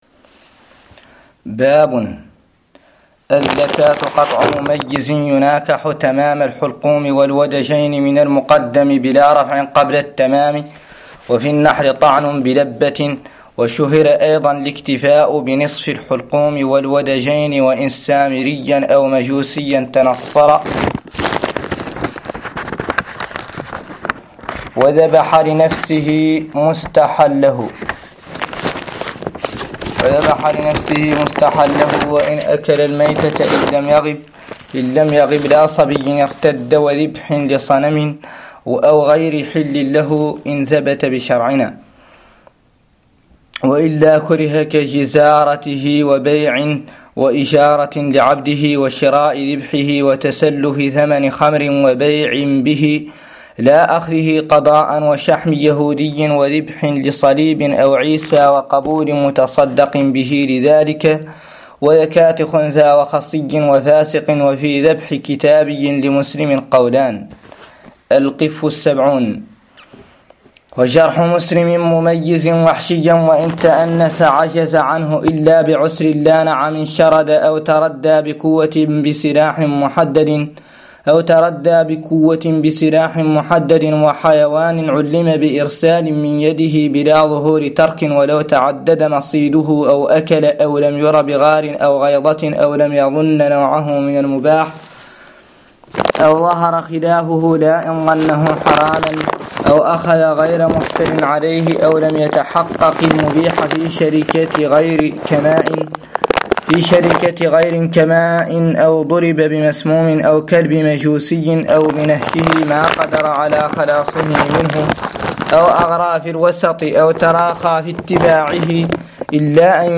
قراءة لمتن مختصر خليل 04